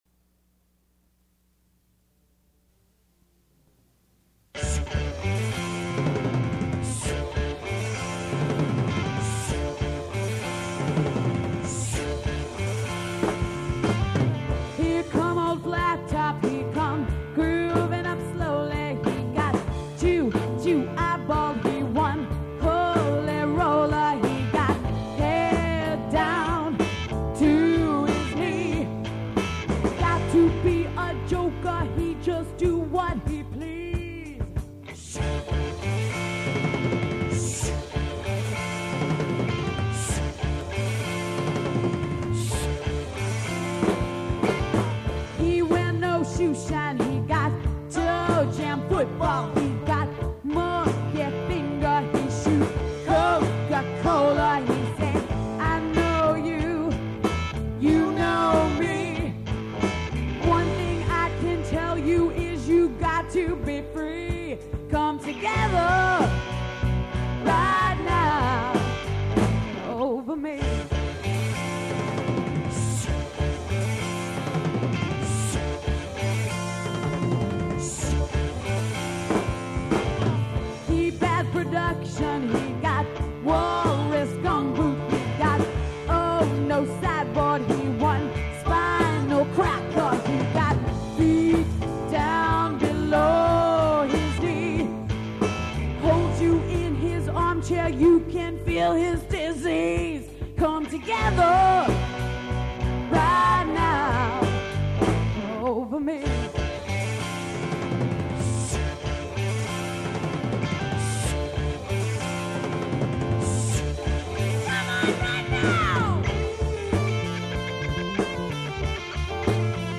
all classic rock